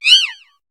Cri de Chacripan dans Pokémon HOME.